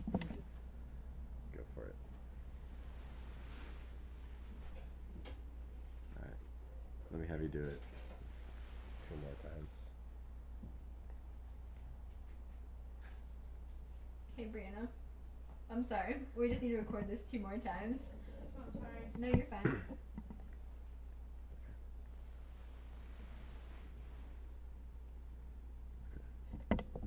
制作3 SFX " 枕头沙沙声序列
描述：在床上固定一个枕头
标签： 枕头
声道立体声